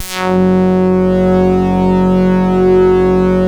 BAND PASS .1.wav